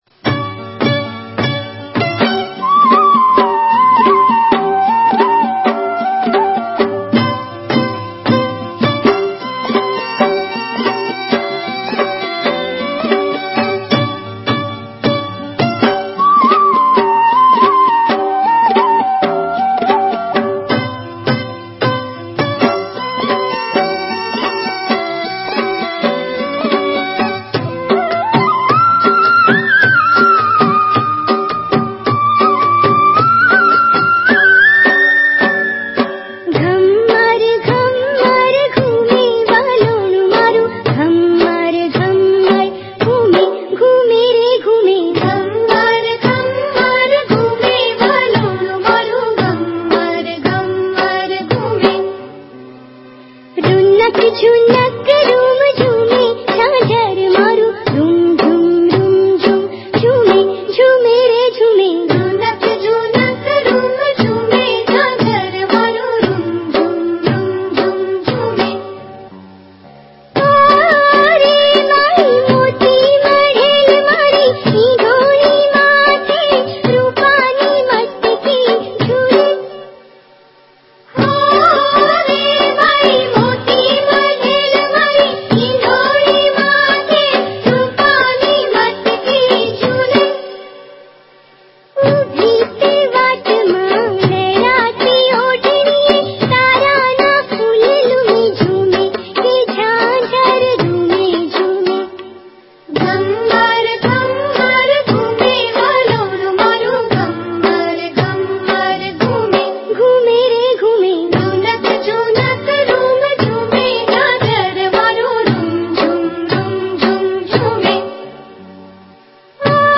ગીત સંગીત લોક ગીત (Lok-Geet)